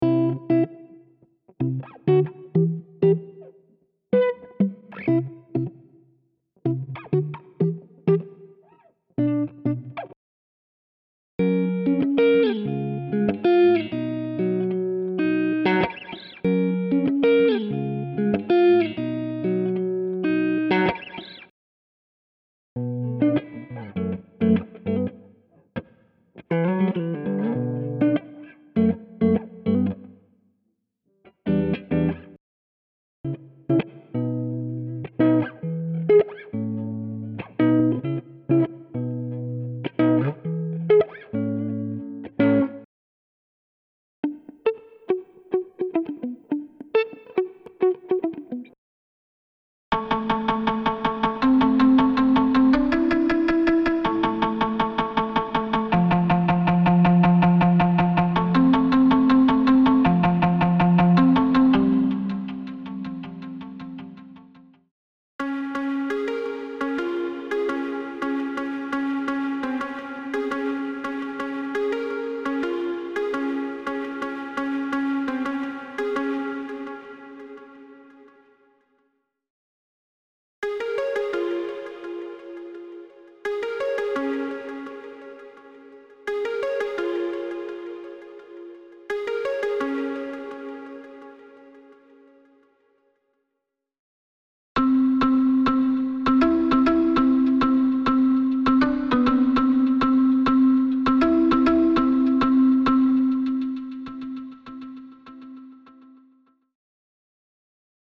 这些即兴片段专门为增加纹理而设计，而不会给现有作品带来忙碌感。
•21 x-（和弦循环）
•29 x-（Riff循环）